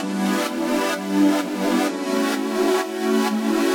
Index of /musicradar/french-house-chillout-samples/128bpm/Instruments
FHC_Pad C_128-E.wav